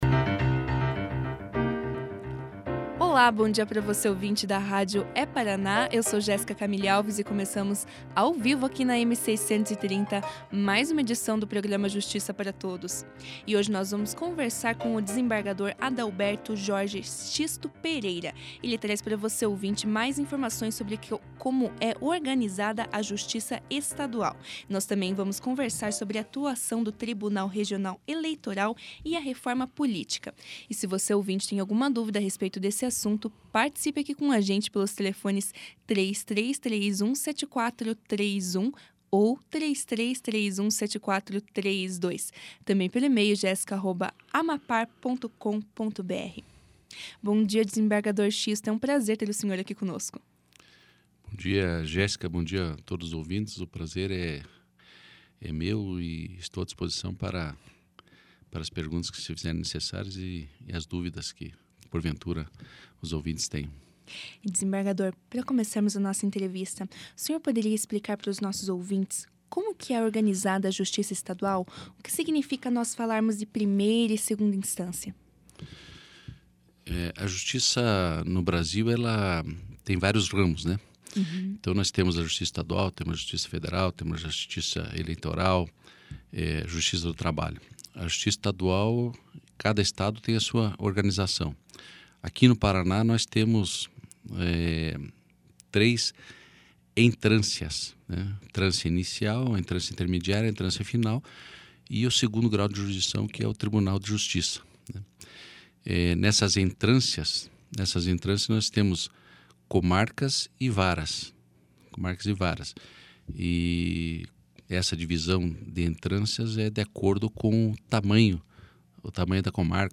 Clique aqui e ouça a entrevista do desembargador Adalberto Jorge Xisto Pereira na íntegra.